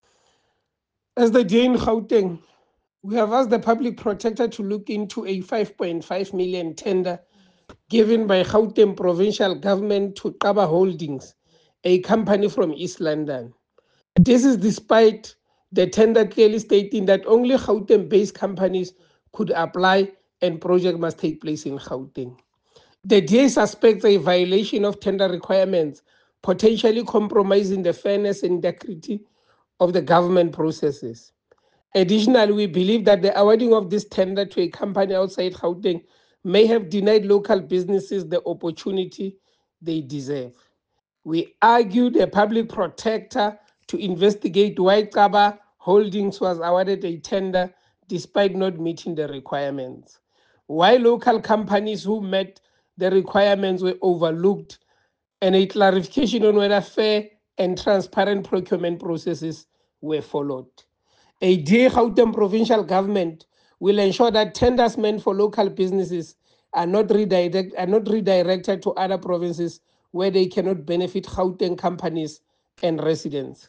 English soundbite by Kingsol Chabalala MPL.